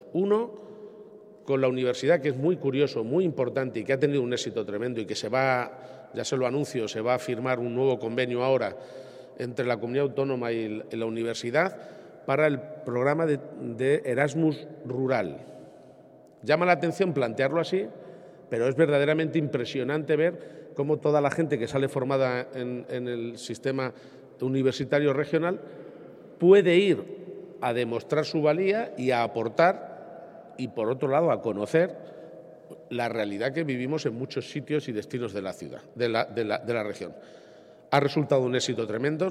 Presidente Martes, 28 Marzo 2023 - 11:30am El presidente García-Page ha avanzado que vamos a volver a firmar de nuevo con la Universidad el convenio del programa del Erasmus Rural, que ha permitido a los jóvenes universitarios demostrar su valía, aportar y además conocer la realidad de muchas partes de nuestra comunidad autónoma, en referencia al medio rural.